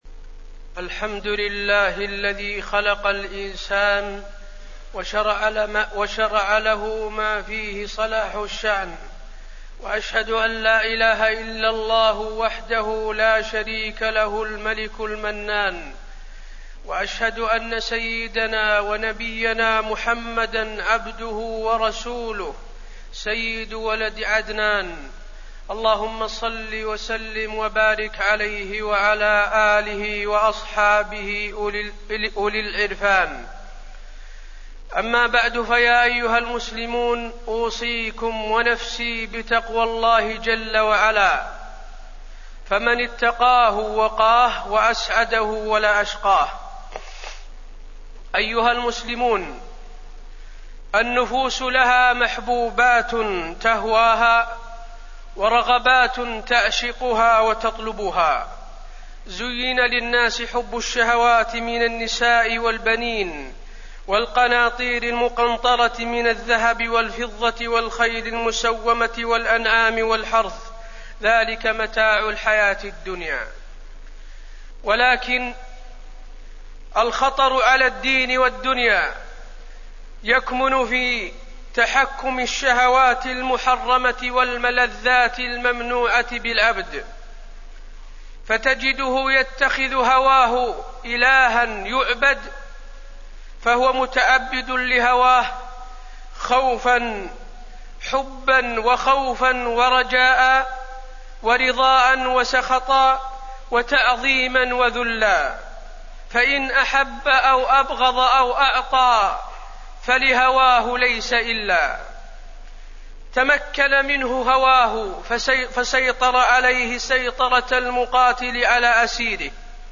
تاريخ النشر ٧ صفر ١٤٣١ هـ المكان: المسجد النبوي الشيخ: فضيلة الشيخ د. حسين بن عبدالعزيز آل الشيخ فضيلة الشيخ د. حسين بن عبدالعزيز آل الشيخ خطر اتباع الهوى